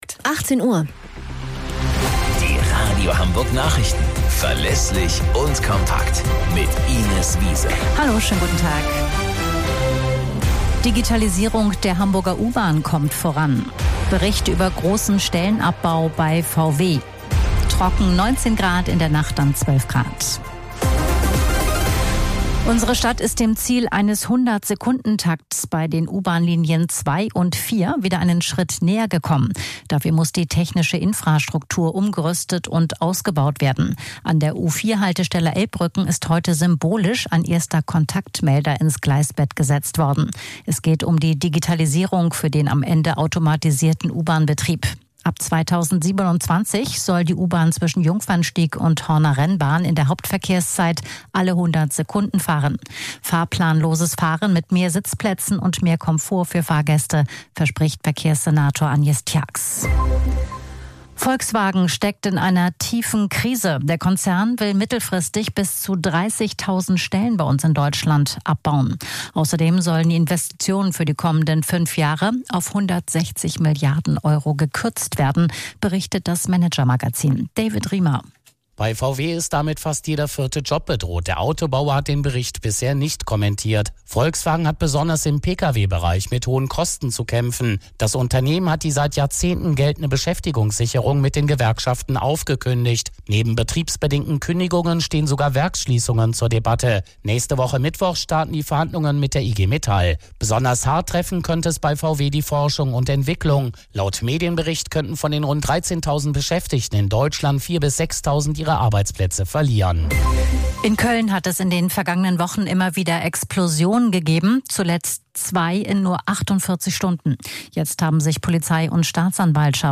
Radio Hamburg Nachrichten vom 27.08.2024 um 00 Uhr - 27.08.2024